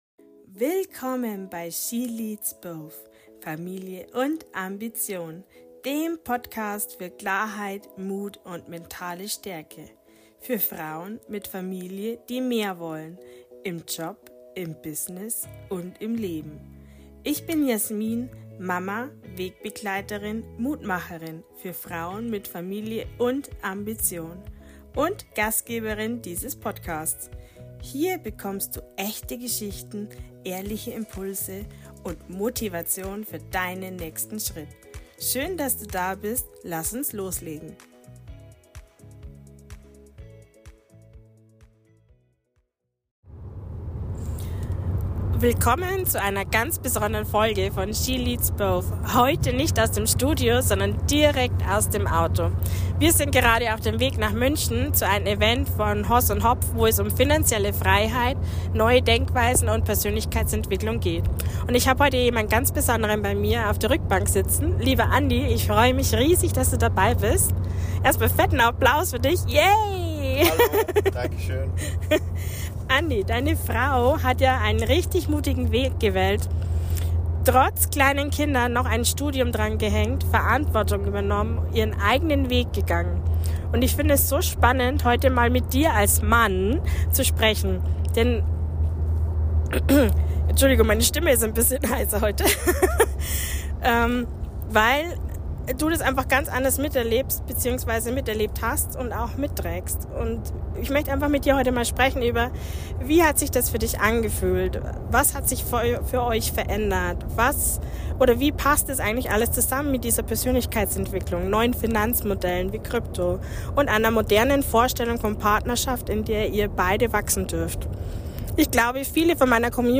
Beschreibung vor 6 Monaten Heute wird’s besonders nah: Ich nehme dich mit ins Auto auf dem Weg nach München zum Hoss-&-Hopf-Event.